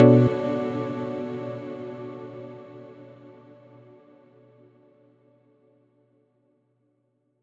menu-multiplayer-click.wav